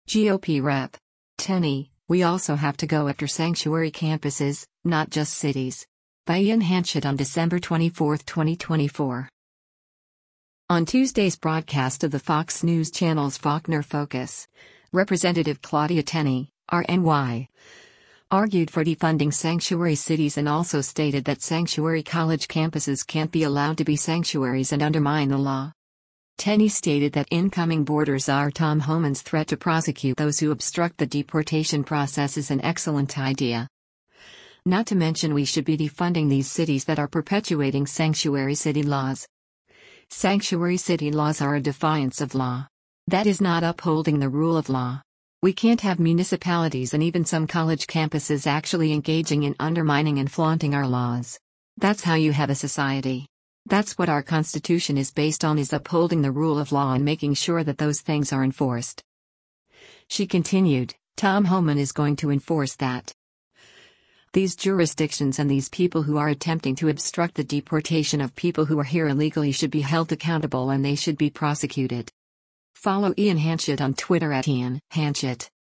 On Tuesday’s broadcast of the Fox News Channel’s “Faulkner Focus,” Rep. Claudia Tenney (R-NY) argued for defunding sanctuary cities and also stated that sanctuary college campuses can’t be allowed to be sanctuaries and undermine the law.